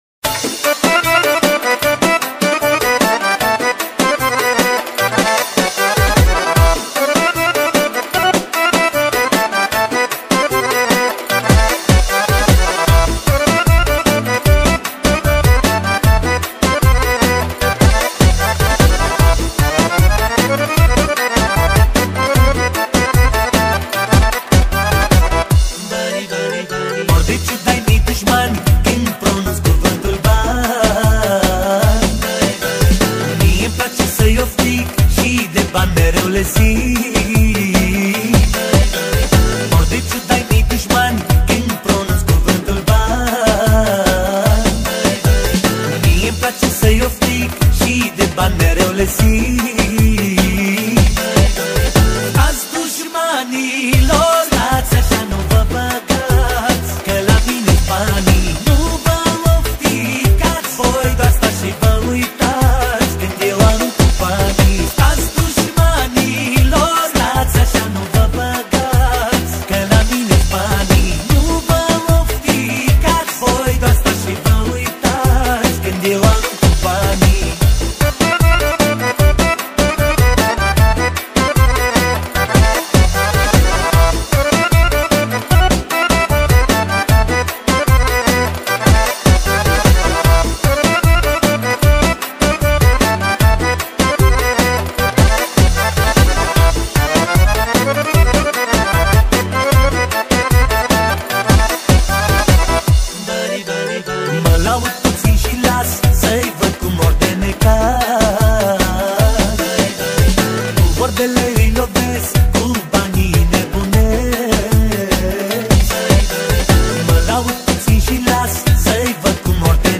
Categoria: Manele New-Live